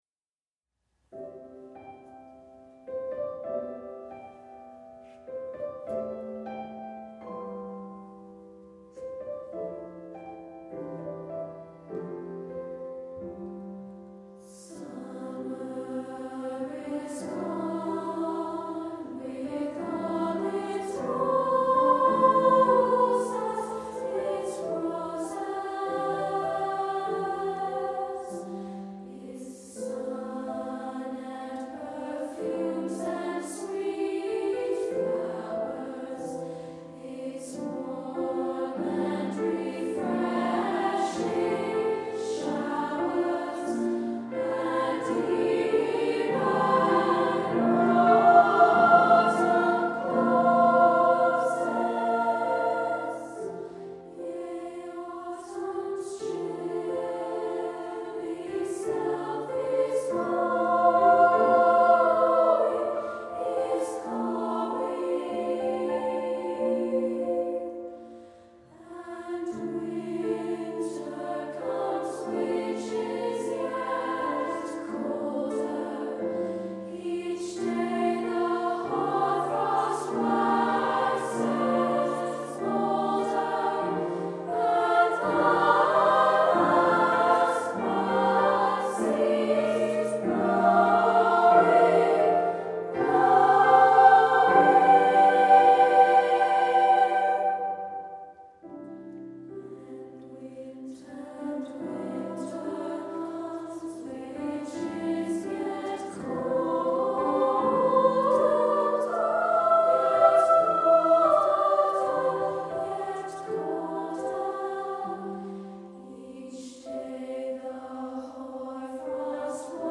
Voicing: 2-Part Treble